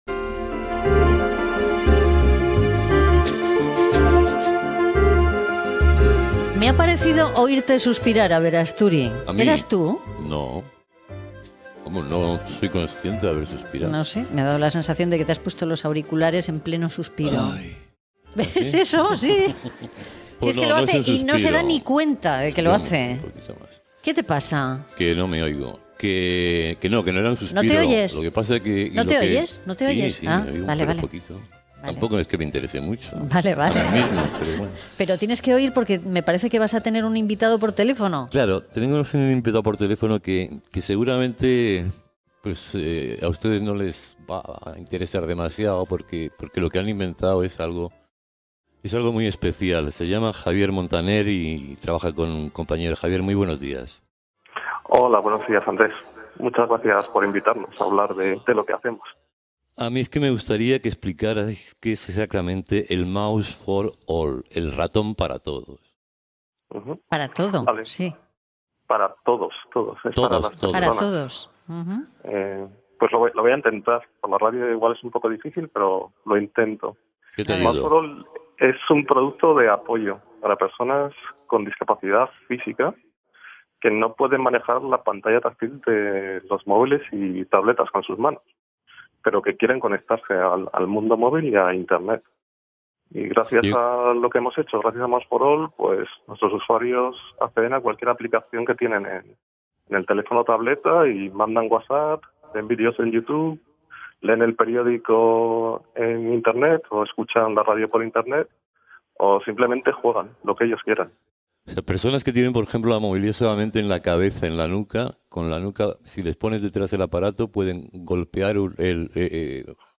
Entrevista telefònica